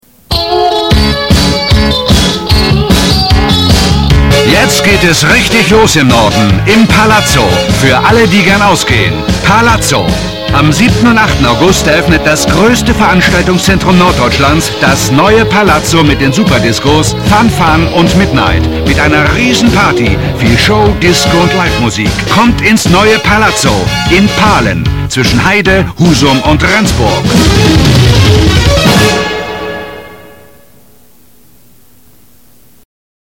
deutscher Schauspieler und Sprecher.
norddeutsch
Sprechprobe: Werbung (Muttersprache):